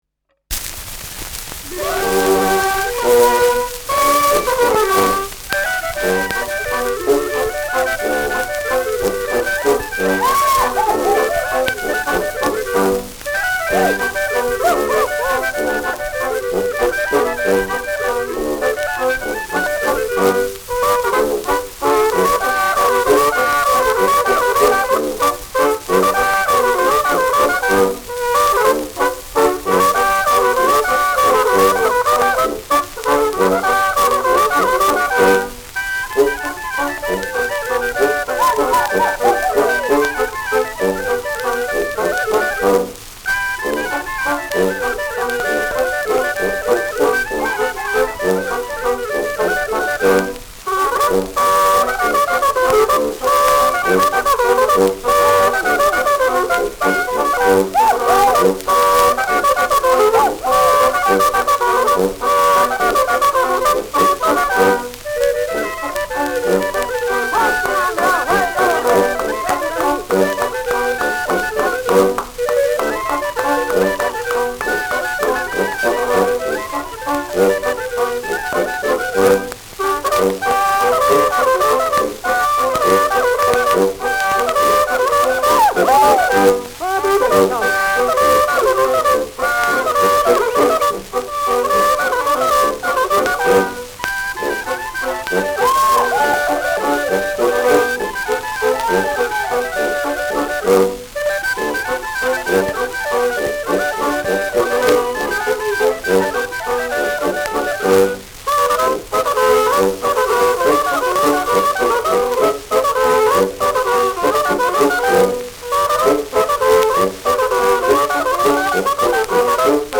Schellackplatte
präsentes Rauschen : präsentes Knistern : abgespielt : gelegentliches Knacken : leichtes Leiern : „Schnarren“
Niederösterreichische Bauernkapelle (Interpretation)
Juchzer, Klopfgeräusche, Zwischenrufe.